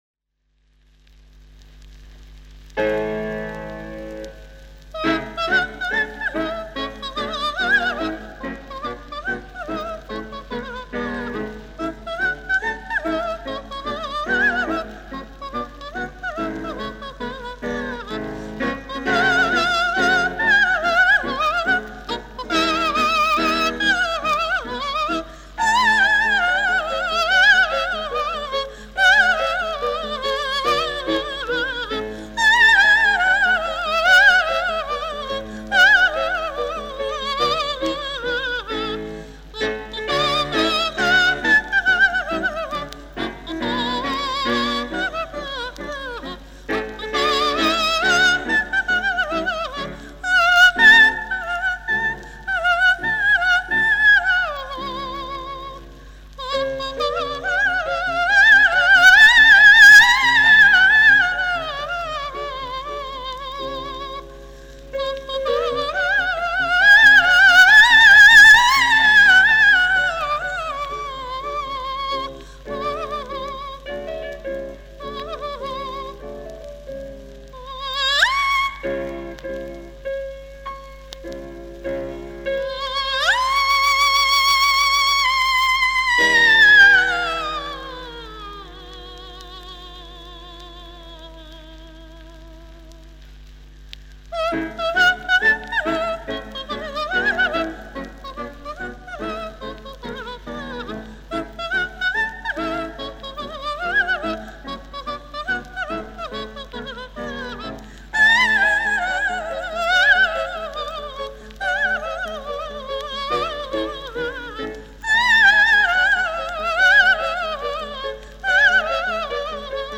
ВОКАЛИЗ – это произведение, написанное для  голоса без слов.